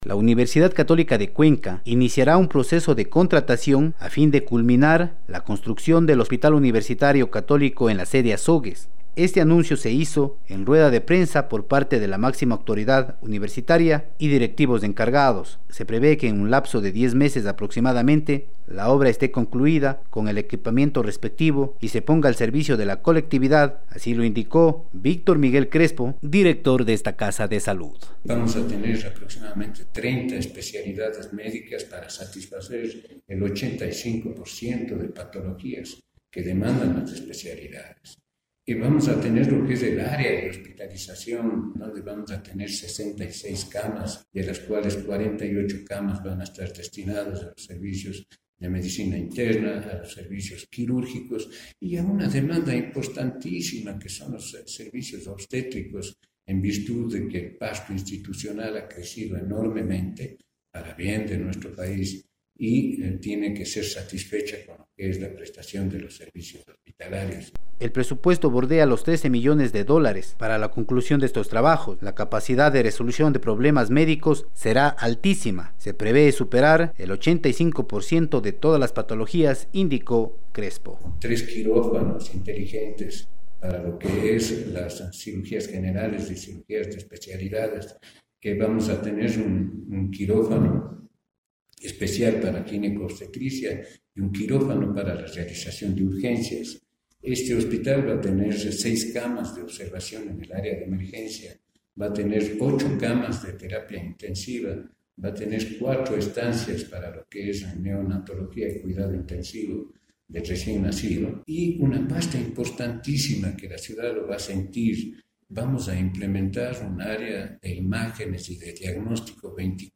Autoridades de la Universidad Católica de Cuenca en rueda de prensa dieron a conocer a la colectividad que en diez meses a partir de la fecha se prevé concluir con los trabajos en lo que respecta a la construcción del Hospital Universitario Católico en Azogues y paralelo a ello la implementación de equipos médicos y personal capacitado para su funcionamiento.